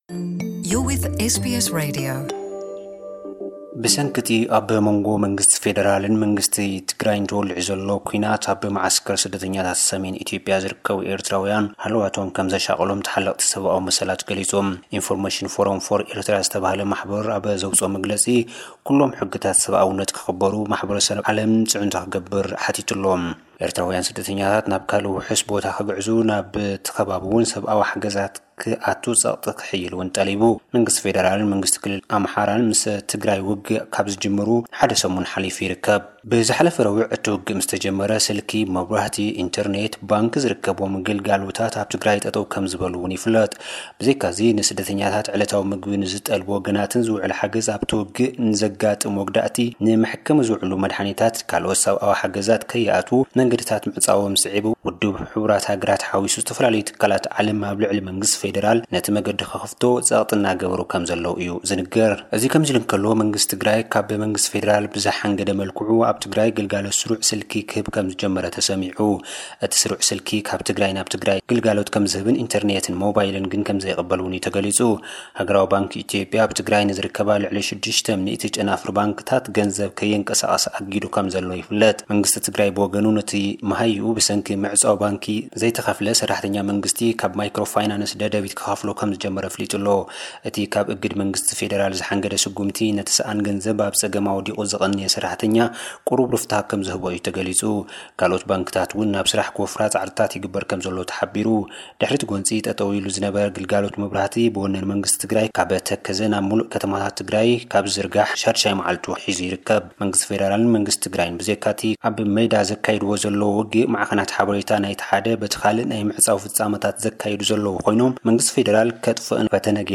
ሱዳን ካብ ኢትዮጵያን ንዝስደዱ ኣማኢት ኣሻሕት ስደተኛታት ንምቕባል ትዳሎ። (ጸብጻብ)